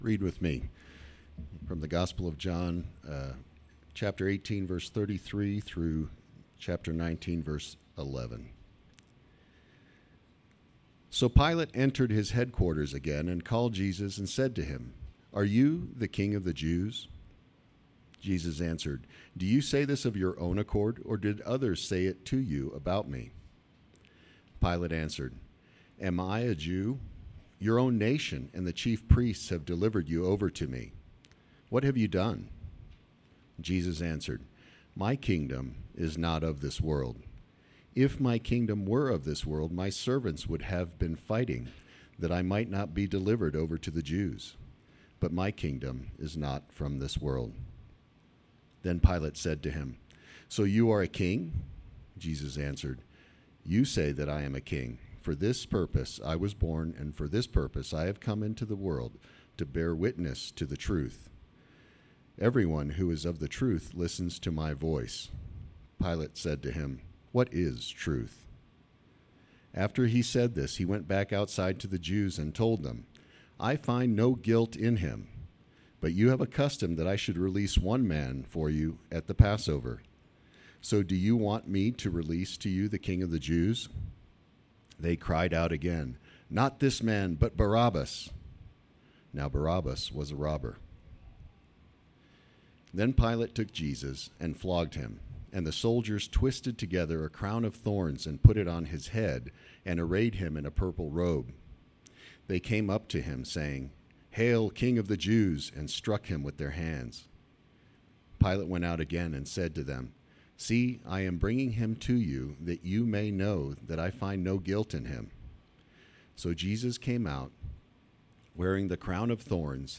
Sermons - Redeemer Presbyterian Church